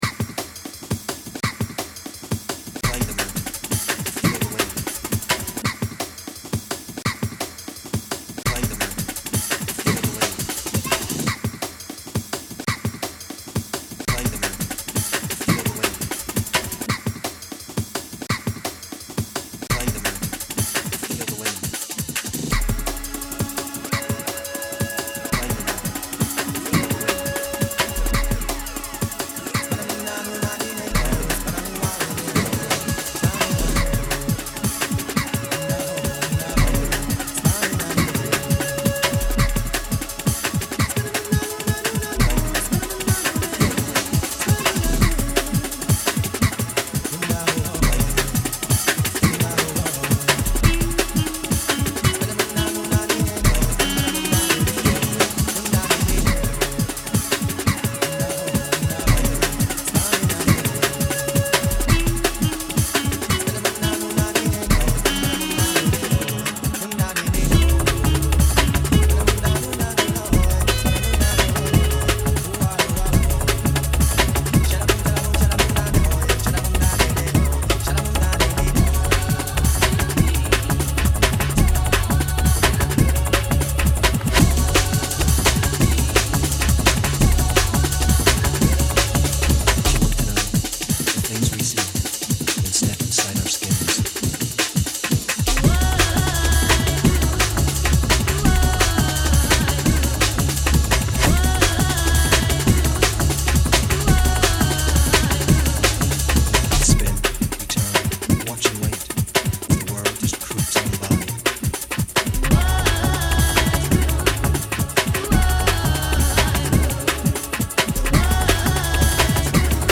So I knocked out a jungle remix!